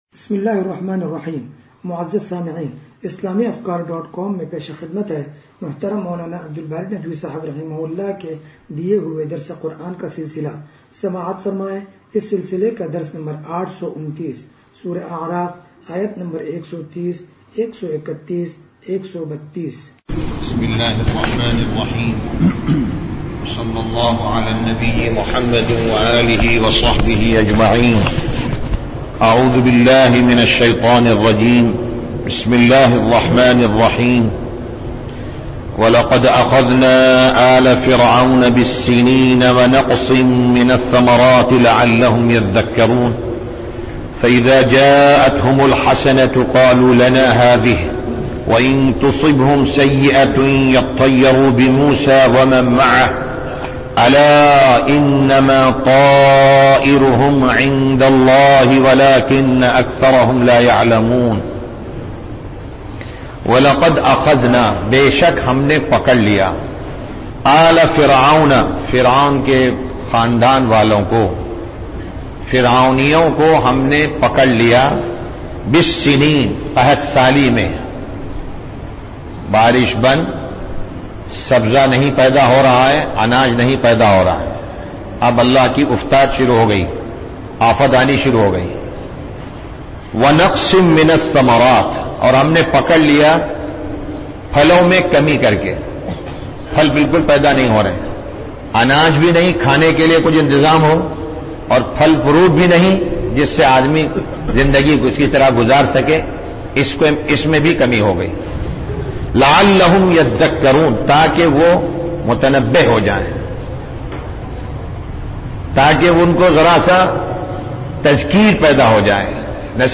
درس قرآن نمبر 0829